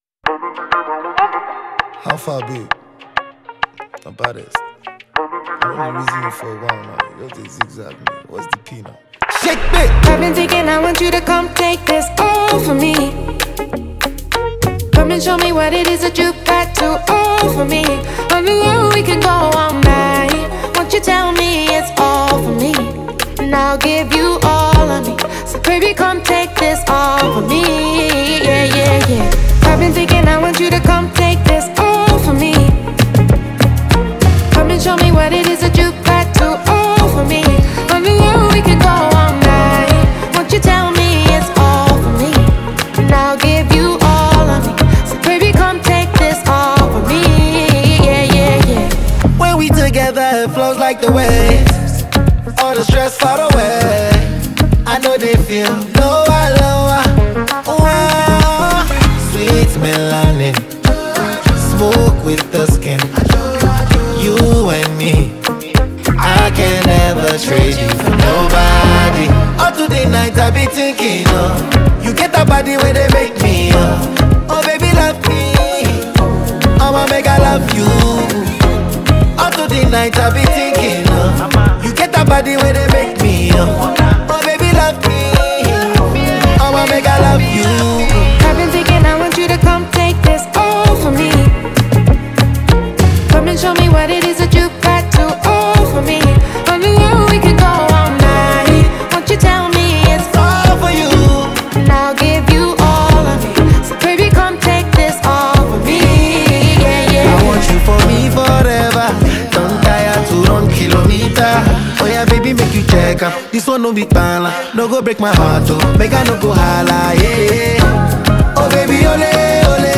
Afro R&B